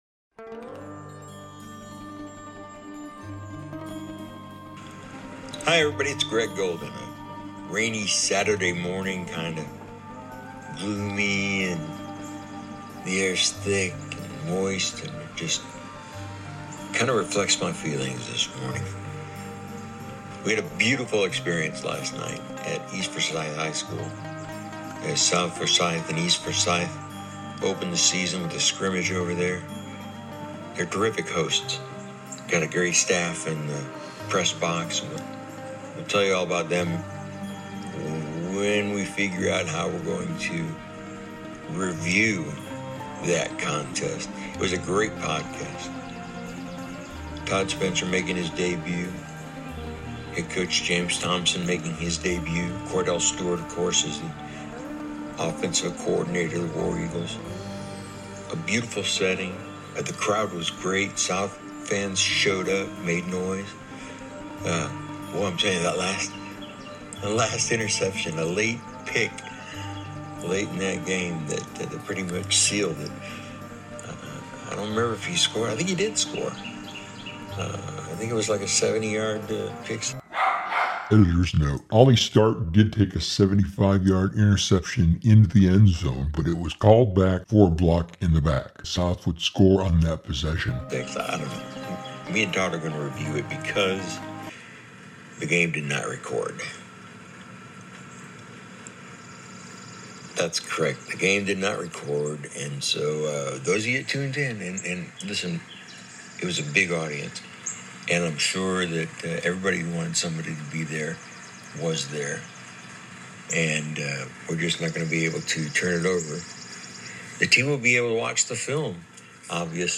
Thanks for reading. if you’ve gotten to the end of this and you’re ready to listen to my solo podcast, you too are a SPORTS FREAK!!